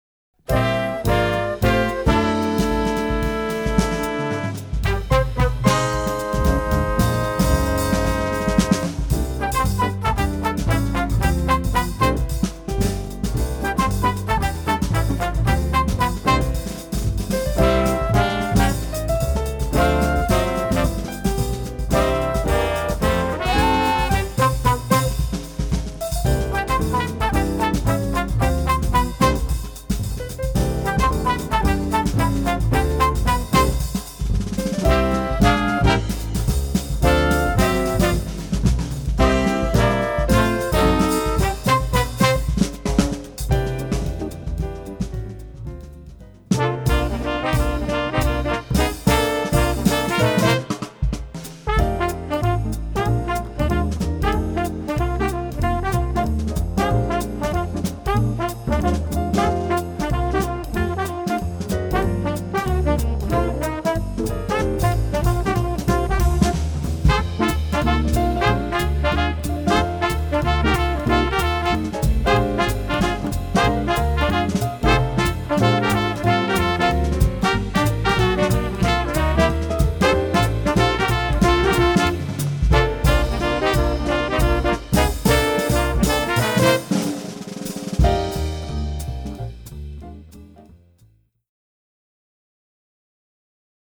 Voicing: Combo w/ Audio